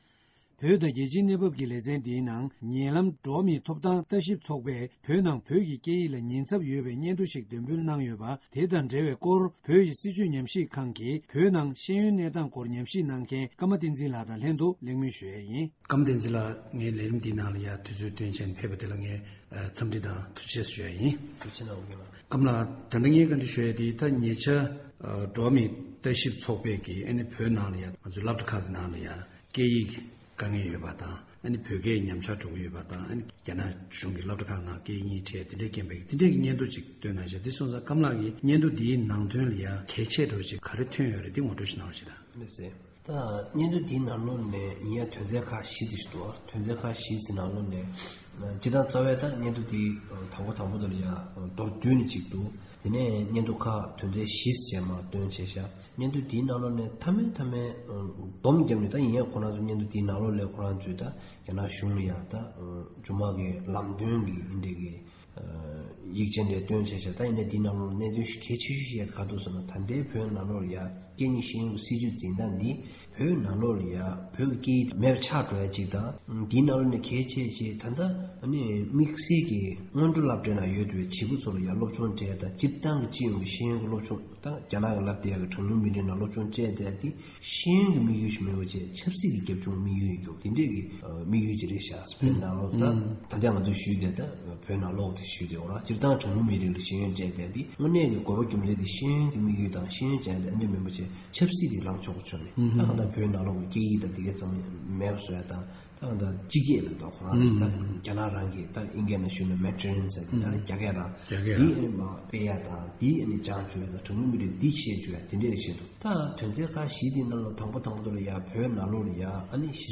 བཀའ་འདྲི་ཞུས་པ།